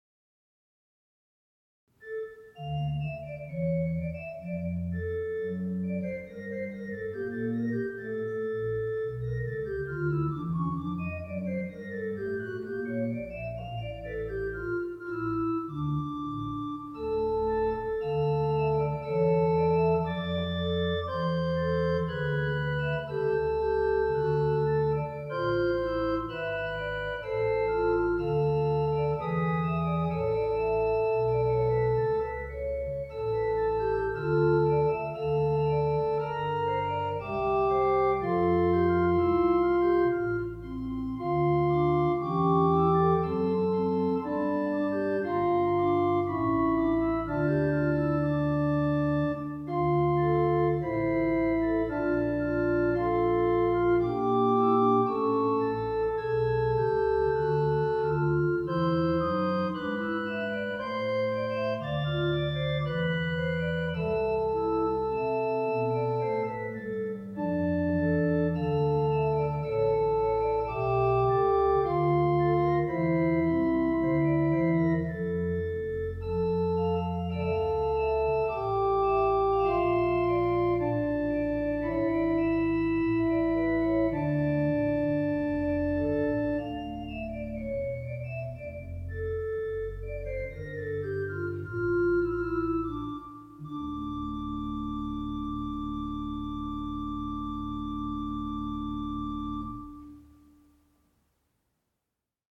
Deze (niet professionele) opnames geven een indruk van de sfeer en mogelijkheden van verschillende orgels op diverse locaties.
Welke registraties doen het goed bij trio spel?